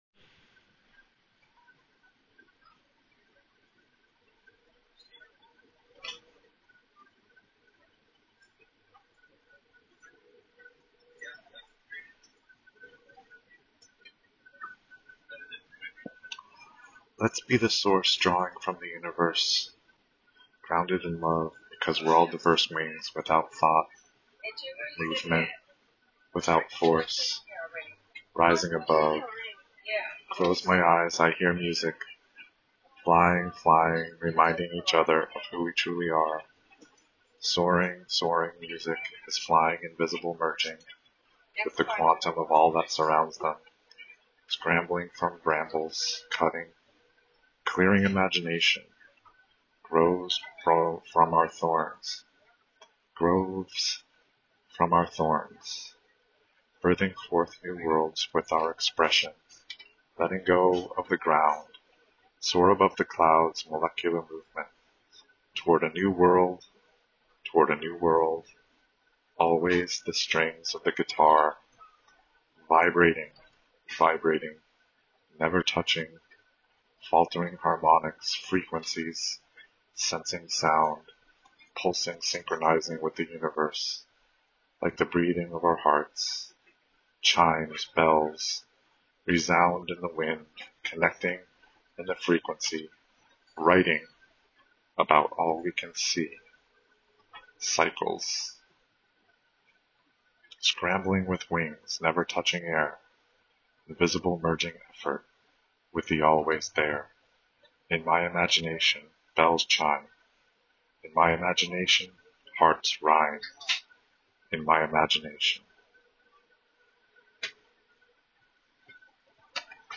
Just read the lyric collab from the livestream
This makes me want to hear more spoken word from you!